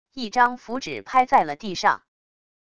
一张符纸拍在了地上wav音频